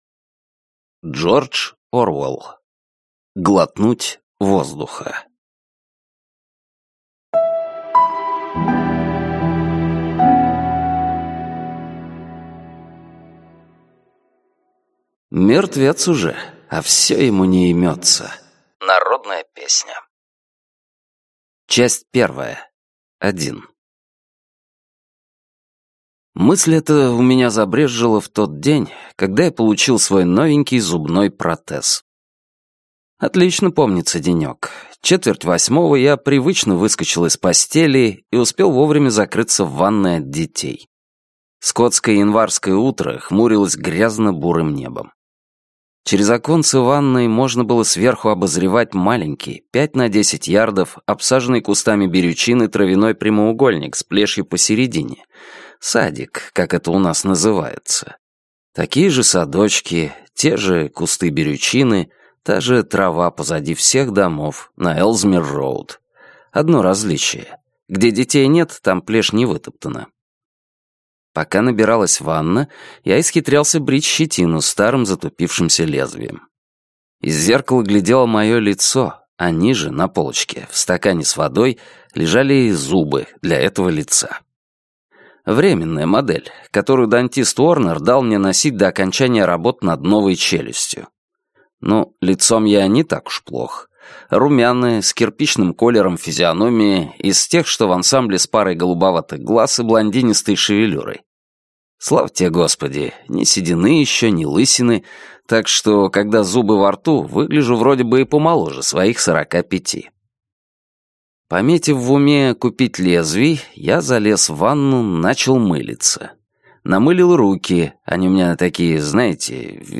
Аудиокнига Глотнуть воздуха | Библиотека аудиокниг